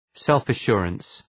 {,selfə’ʃʋrəns}